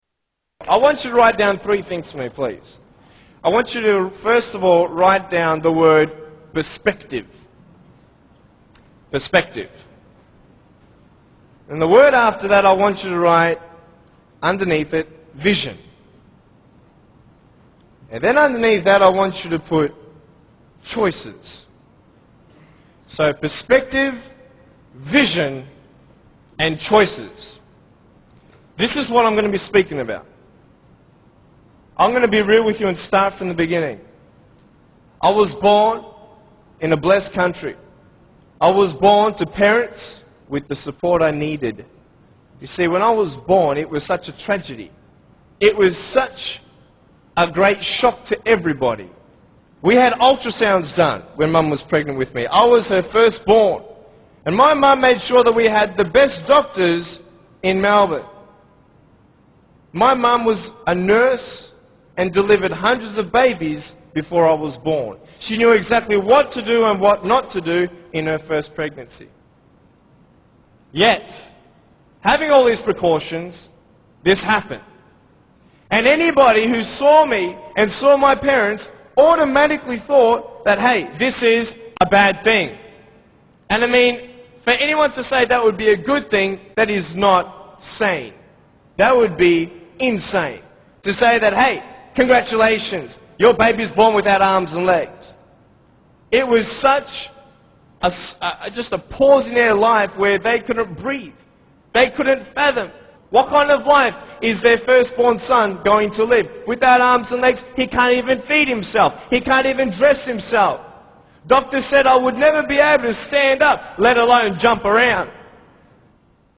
Motivator speaks prt 1